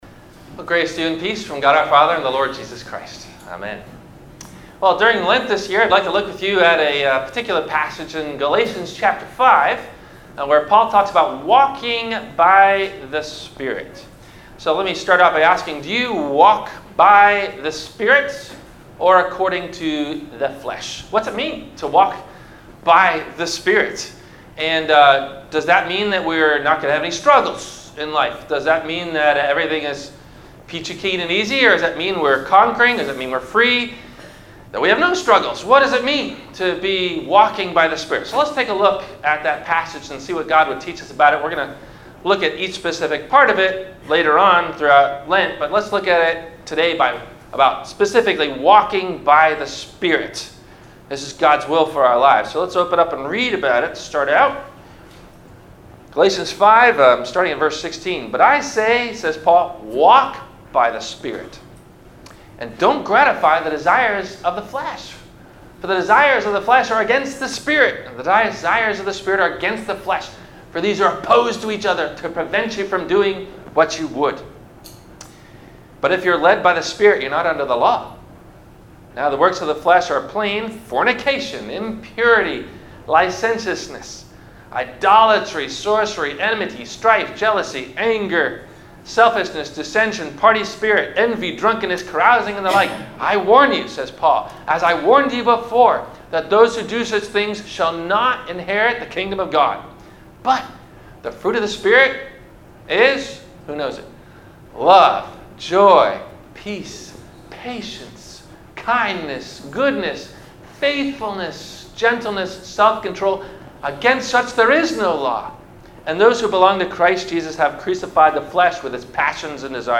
– Sermon – Ash Wednesday Lent – February 14 2018